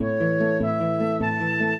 flute-harp
minuet3-2.wav